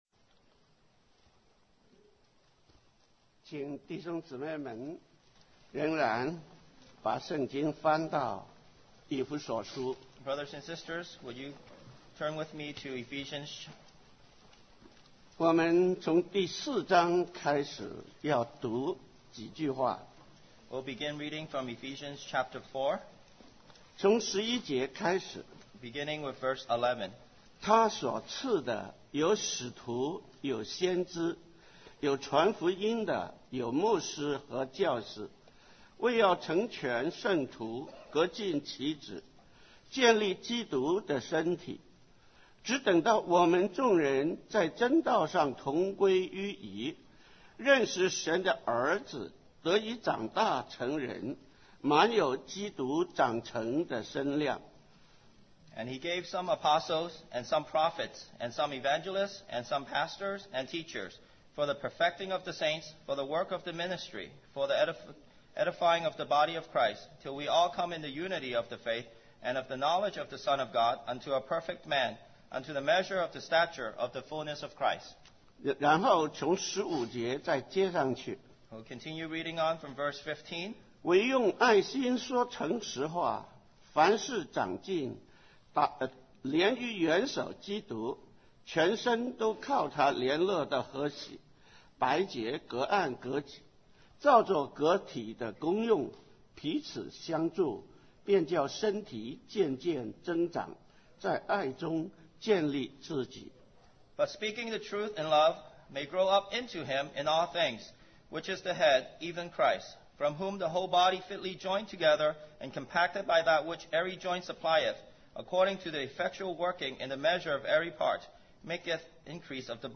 West Coast Christian Conference